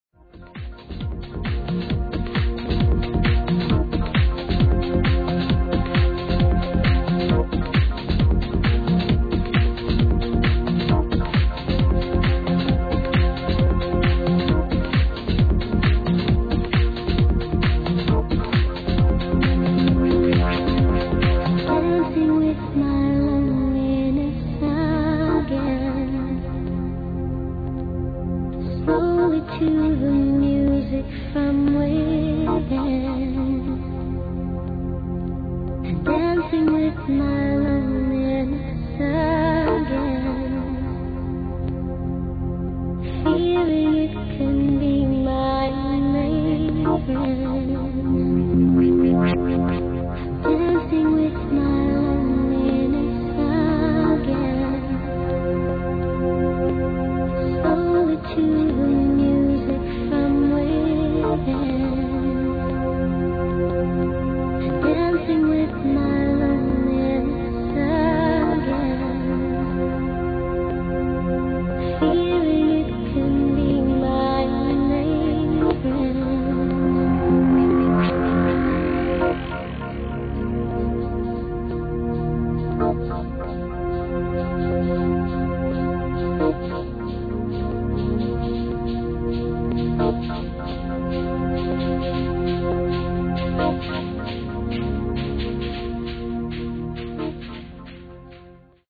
Ahh, beautiful vocal tune.